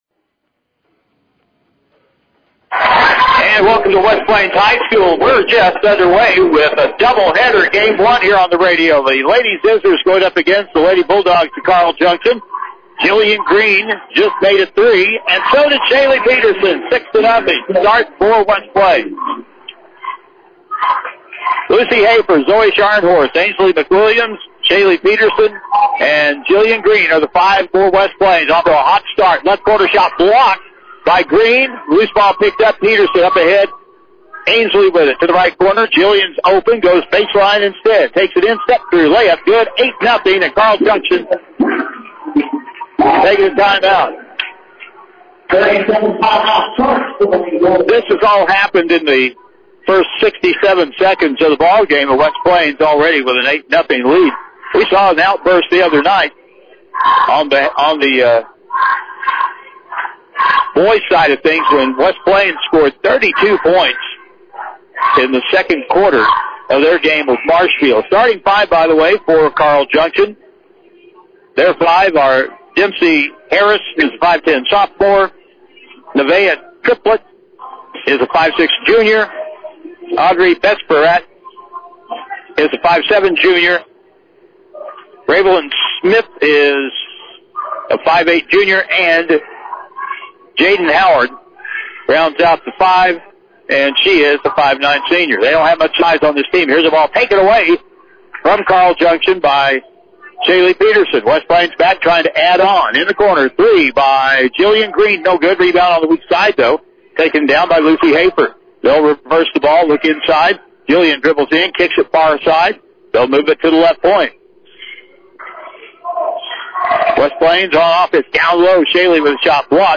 The West Plains Lady Zizzers kicked off a double header home at The West Plains High School on Friday Night, February 20th, 2026 as both teams took on The Carl Junction Bulldogs & Lady Bulldogs.